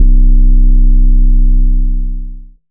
DDW2 808 7.wav